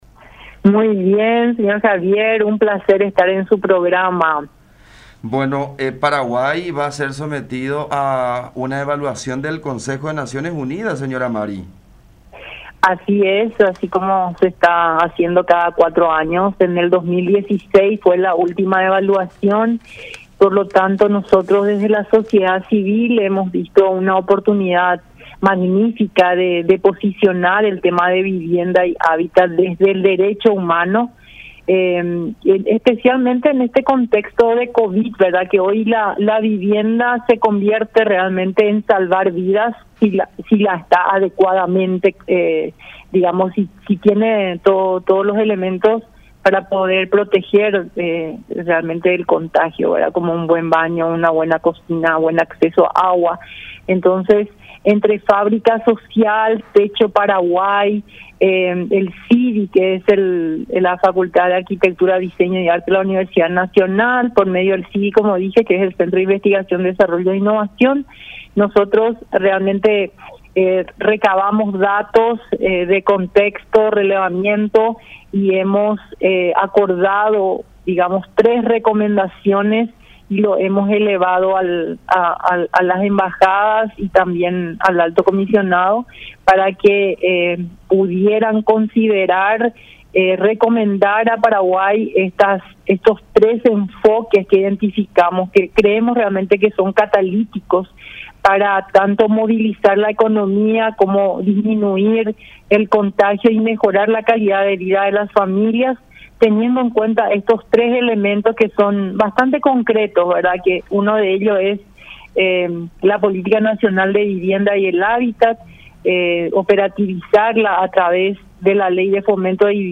en contacto con La Unión R800 AM.